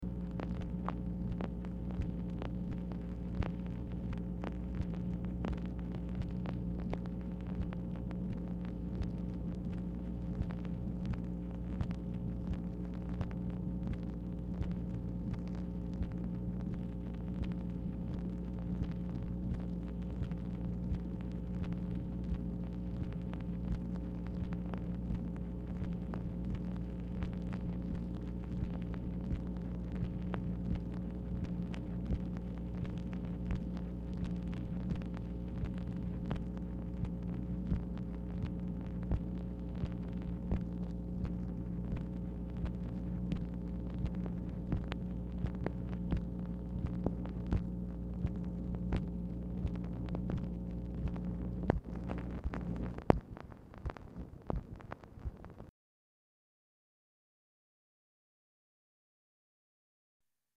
Telephone conversation # 11630, sound recording, MACHINE NOISE, 3/10/1967, time unknown | Discover LBJ
Format Dictation belt
White House Telephone Recordings and Transcripts Speaker 2 MACHINE NOISE